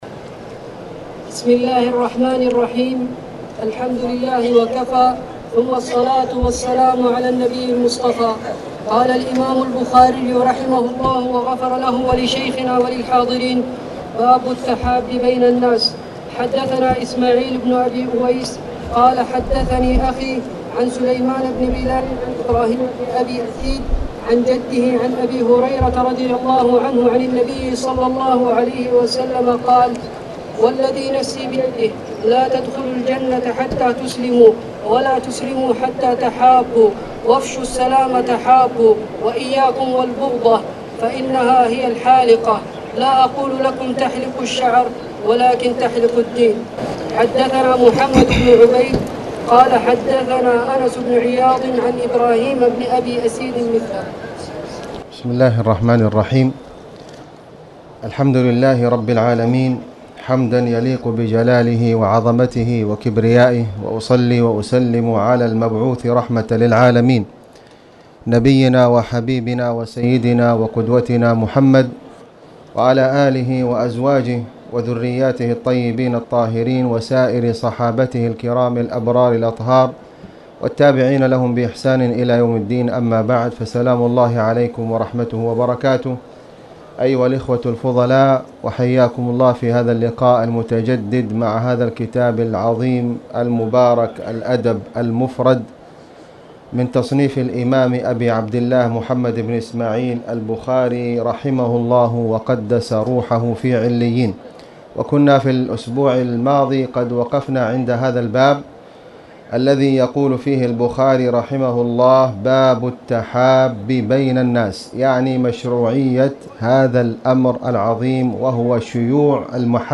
تاريخ النشر ٢٣ جمادى الأولى ١٤٣٨ هـ المكان: المسجد الحرام الشيخ: خالد بن علي الغامدي خالد بن علي الغامدي باب التحاب بين الناس